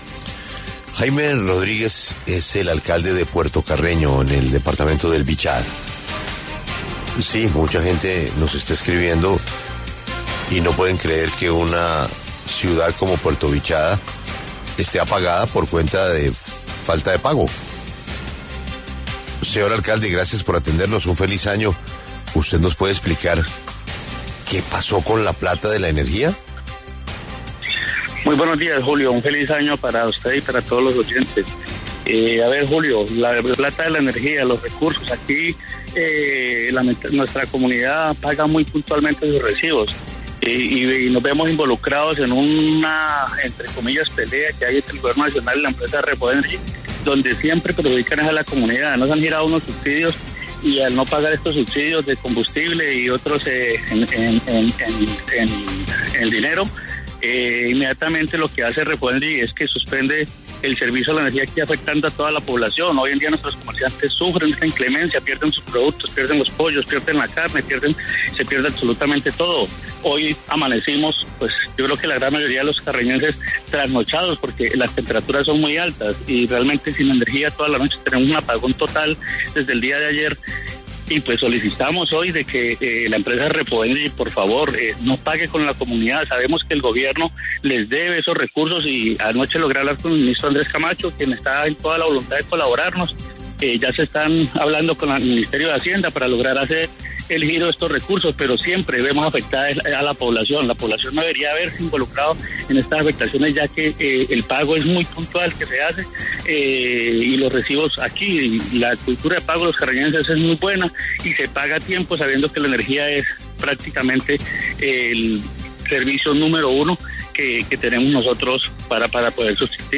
El alcalde de Puerto Carreño, Jaime Rodríguez, denunció en entrevista con La W, que la ciudad enfrenta un apagón total desde el día de ayer, que ha afectado gravemente a la comunidad.